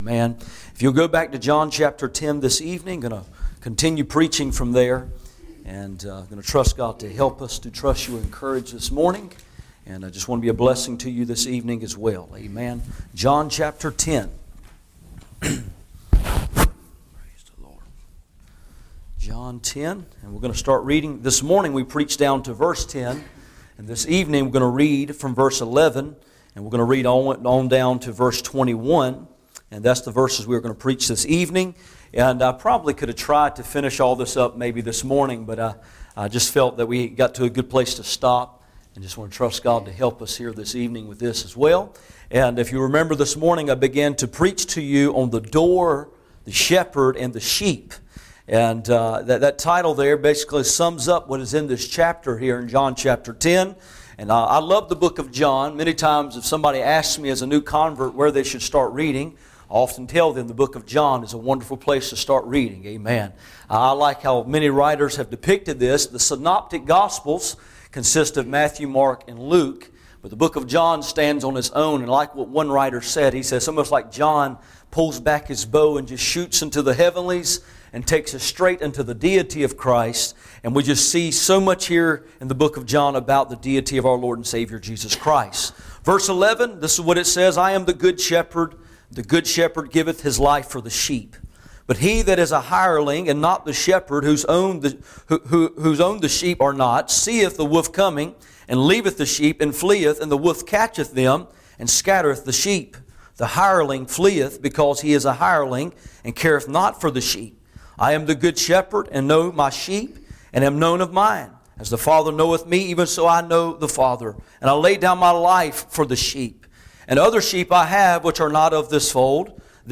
None Passage: John 10:11-21 Service Type: Sunday Evening %todo_render% « The Door